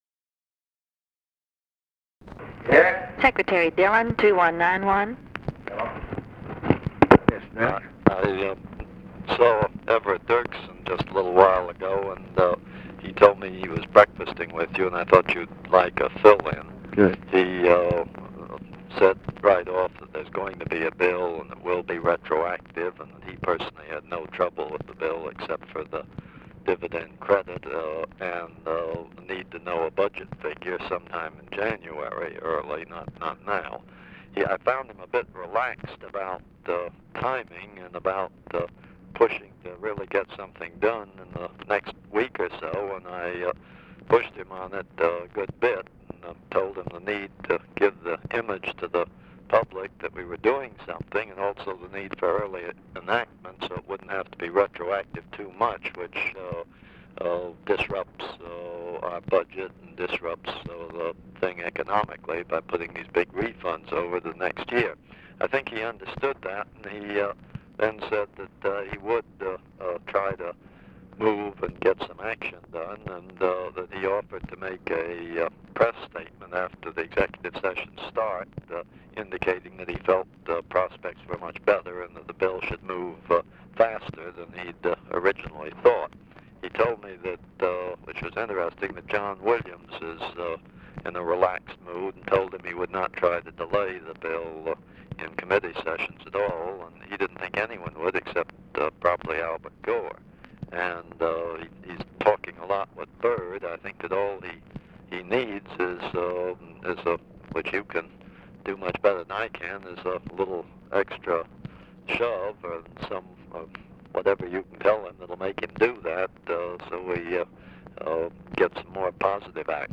Conversation with DOUGLAS DILLON, December 3, 1963
Secret White House Tapes